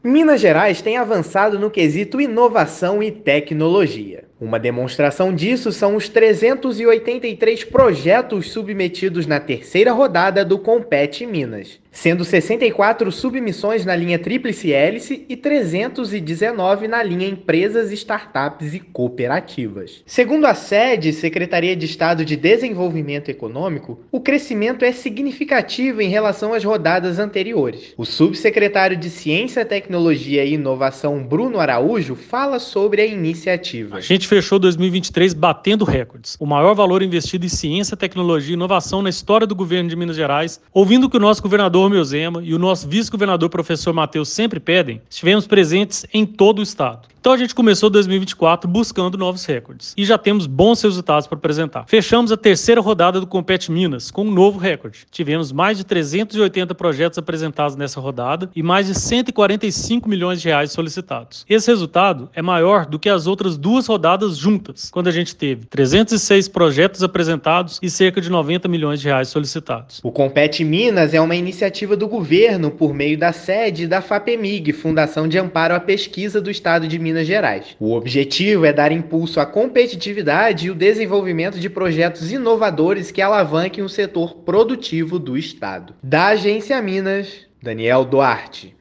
Em ambas as linhas de chamada, Tríplice Hélice e Empresas, Startups e Cooperativas, foram mais de 380 projetos submetidos, quase o dobro do registrado na rodada anterior. Ouça a matéria de rádio: